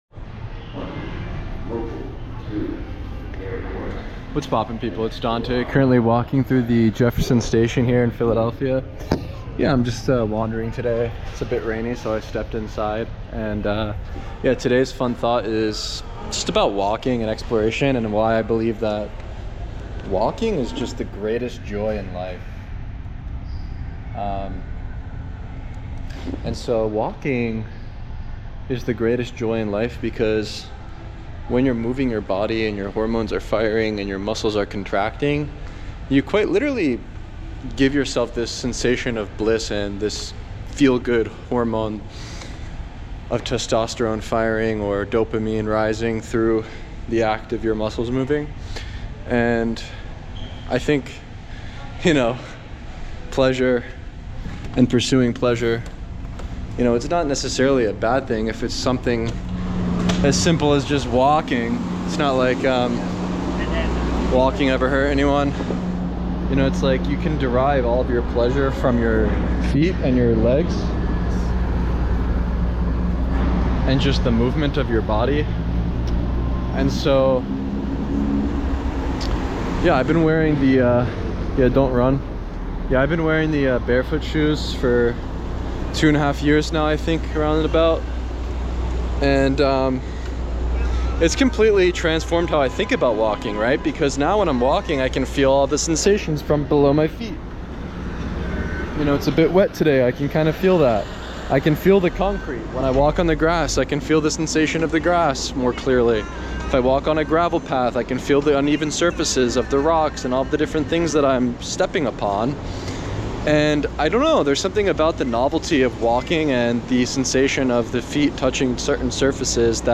It’s a bit rainy today, so I stepped inside. And yeah—today’s fun thought is about walking and exploration and why I truly believe walking is one of the greatest joys in life.